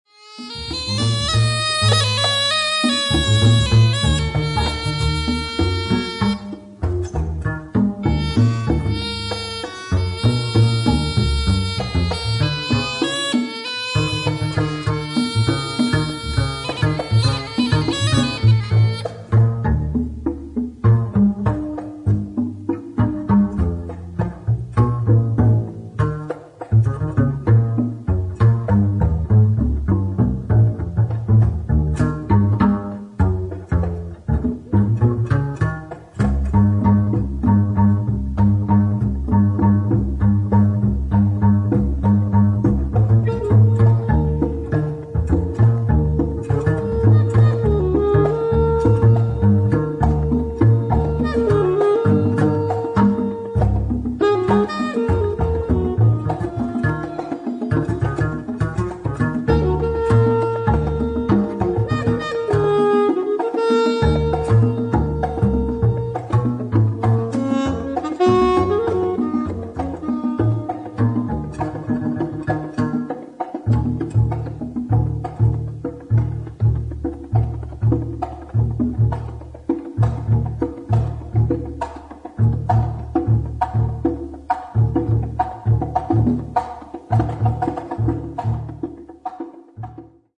シンプルながらも独特のムードと深みが感じられるアラブ音楽＋ジャズな素晴らしい作品